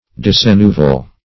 Search Result for " decennoval" : The Collaborative International Dictionary of English v.0.48: Decennoval \De*cen"no*val\, Decennovary \De*cen"no*va*ry\, a. [L. decem ten + novem nine.]